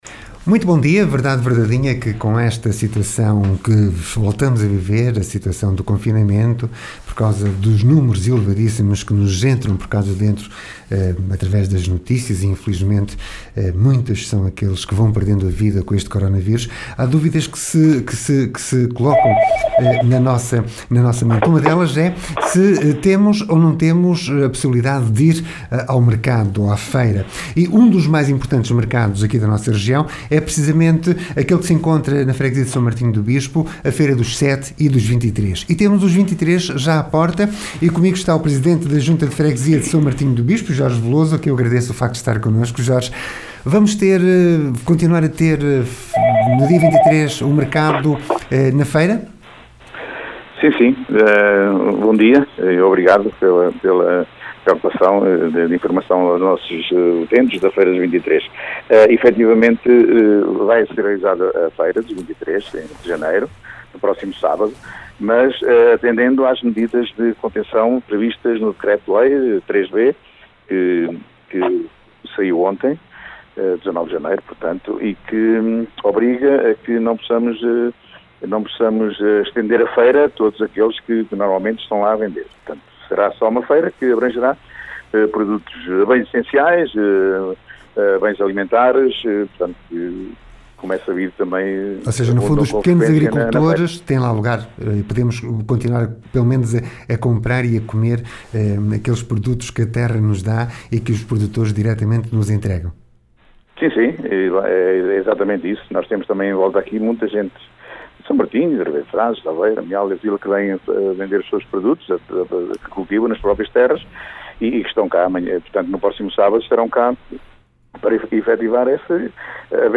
O ciclo da vida nas várias áreas do mundo rural e a conversa com Jorge Veloso, Presidente da União de Freguesias de S. Martinho do Bispo e Ribeira de Frades, que no diz que vai haver Feira dos 23 apenas os produtos agrícolas.